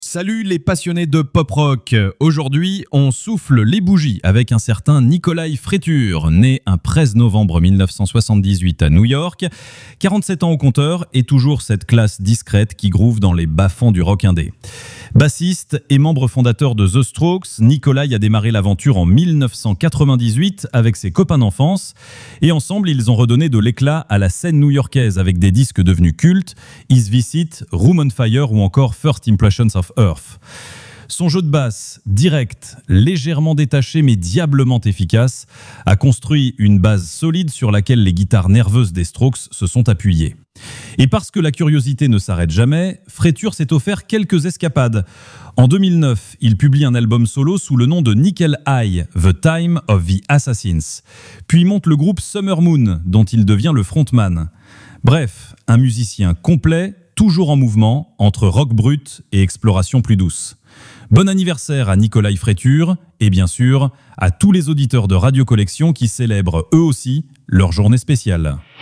Une chronique vivante qui mêle souvenirs, anecdotes et découvertes pour un véritable voyage quotidien dans l’histoire des artistes préférés des fans de Pop Rock, des années 70 à aujourd’hui.